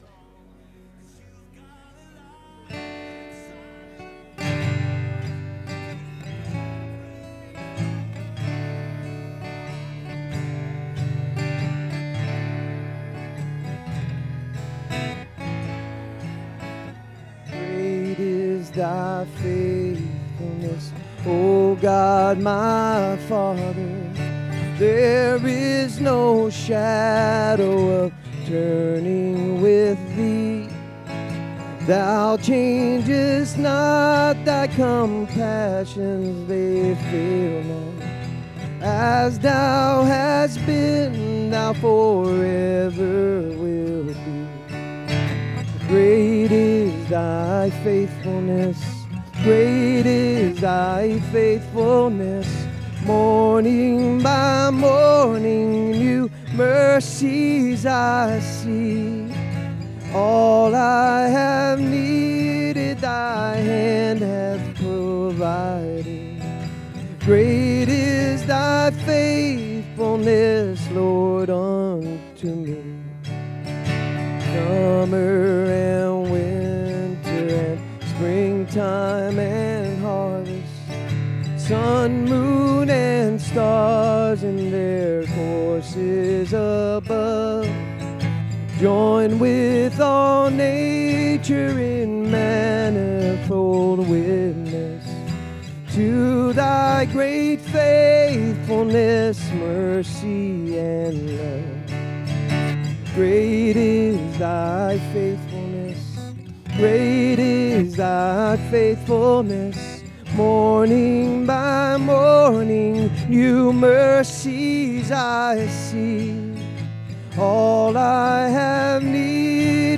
This sermon will explore what it means to be a friend who is truly there in times of need—no matter the hour.